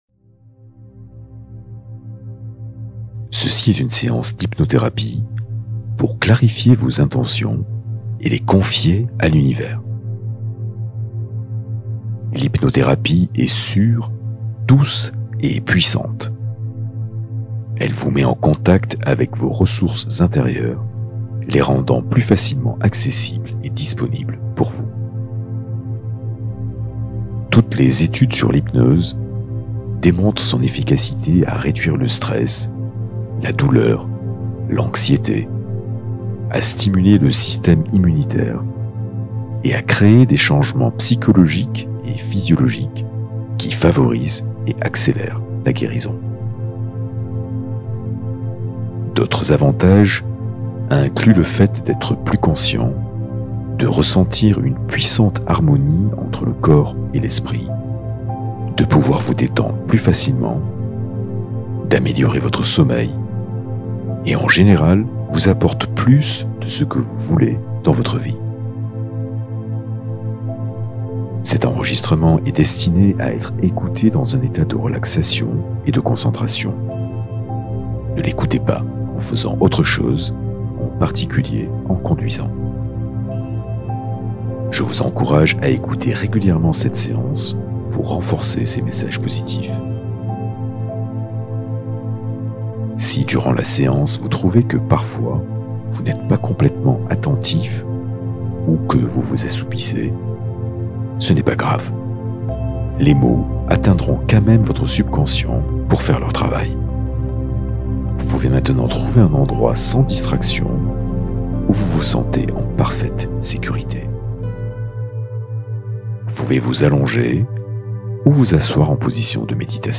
Méditation guidée sur la figure symbolique de l’Ange Gardien